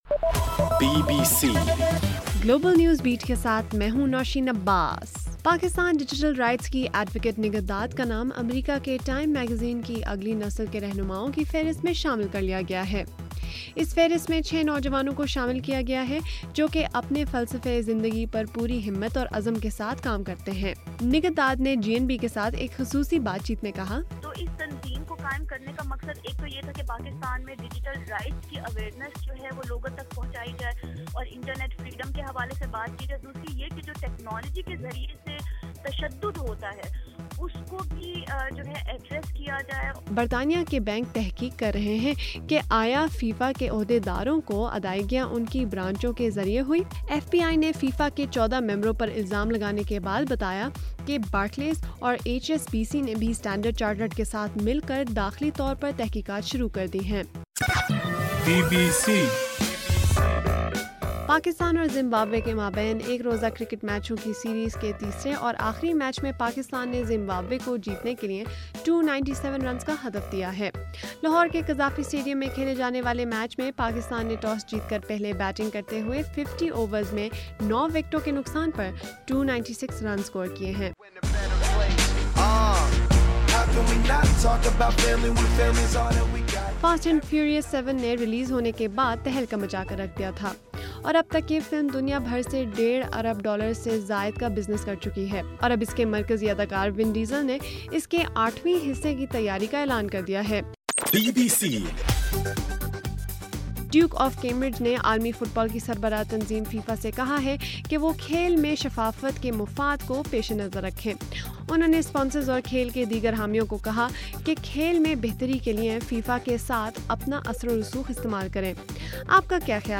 جون 1: صبح 1بجے کا گلوبل نیوز بیٹ بُلیٹن